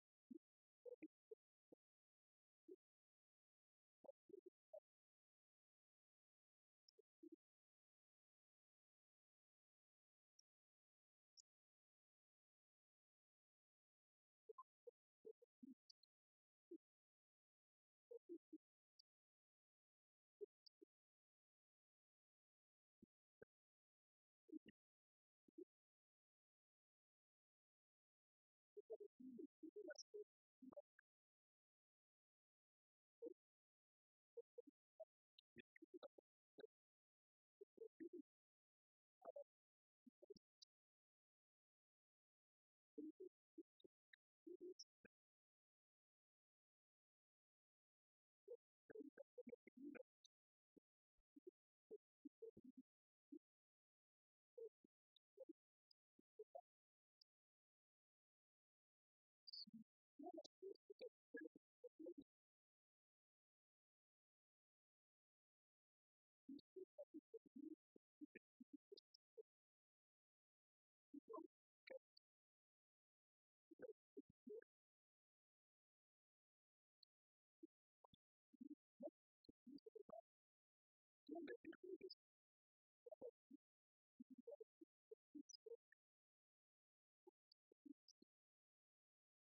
Messe anniversaire à la chapelle Notre-Dame des Sources
Catégorie Témoignage